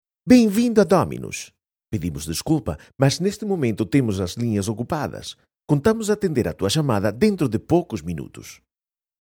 PT EU JM IVR 01 IVR/Phone systems Male Portuguese - Portugal